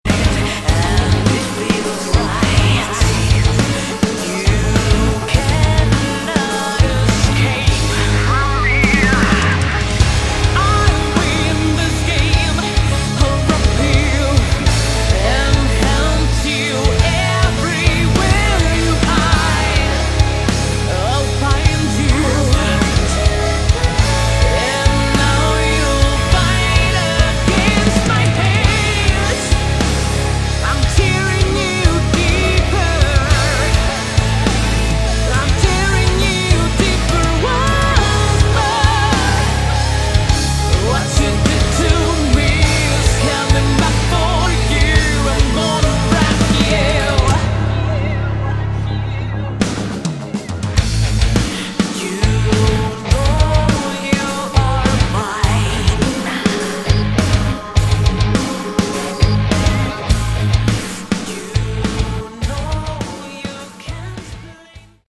Category: Melodic Metal
vocals
keyboards
drums
guitars
bass